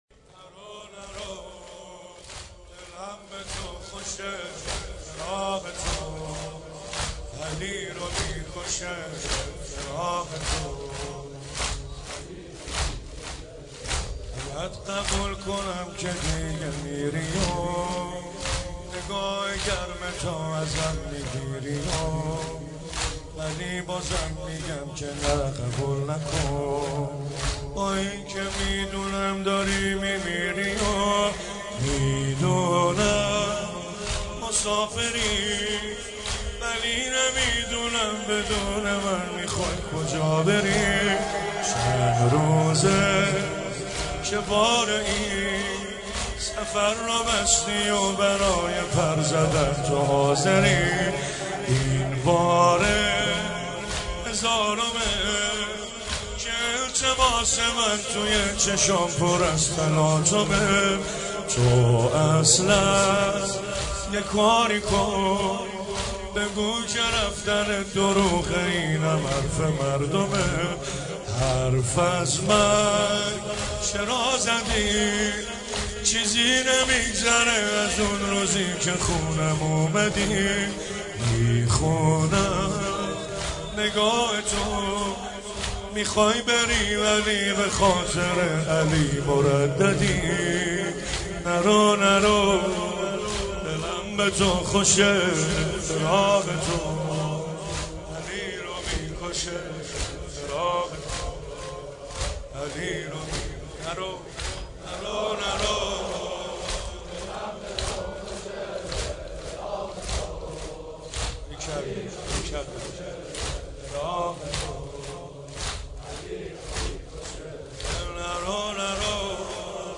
مداحی و نوحه
[سینه زنی زمینه]